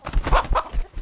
CosmicRageSounds / wav / general / combat / creatures / CHİCKEN / he / attack3.wav
attack3.wav